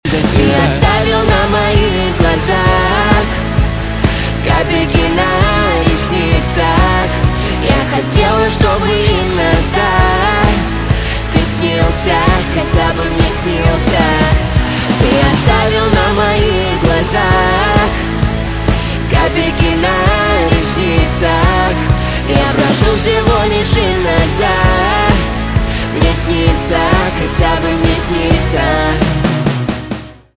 русский рок
саундтрек, грустные
гитара, барабаны